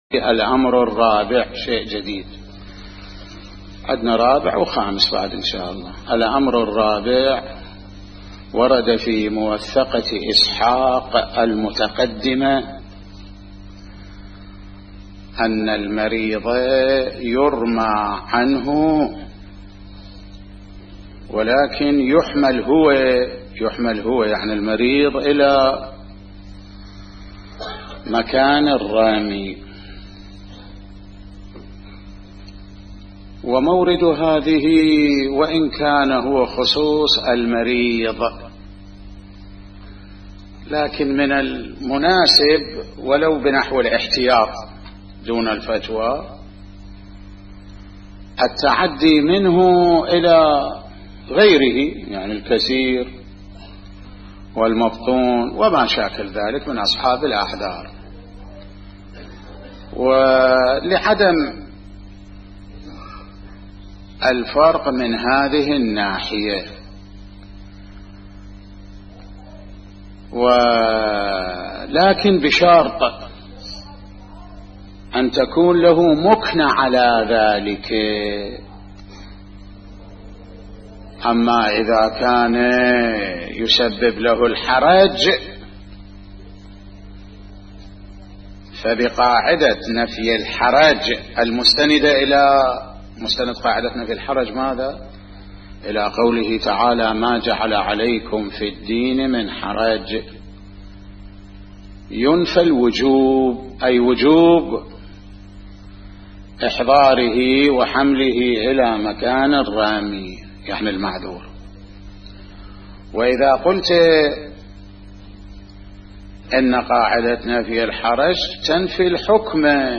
بحث الفقه